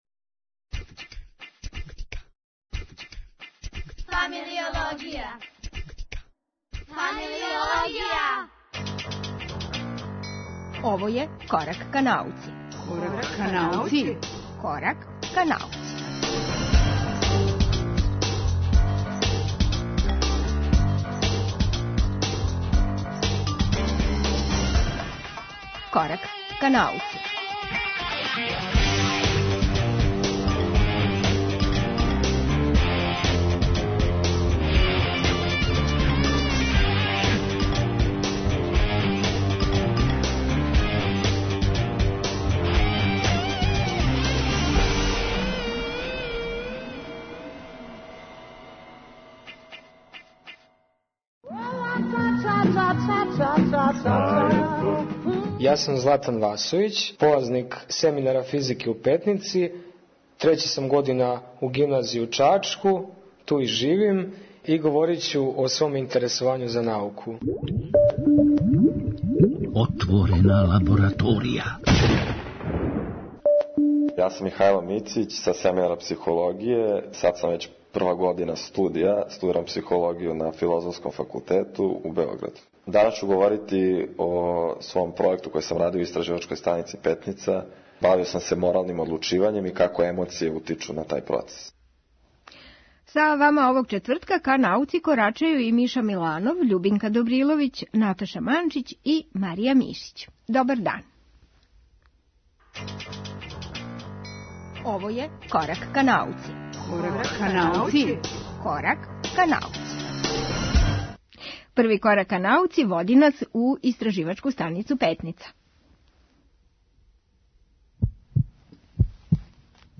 Изазов (нови радни циклус у ИС Петница);Један је Галоа